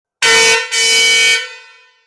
• Som agudo e de alta frequência;
• Intensidade sonora 130db;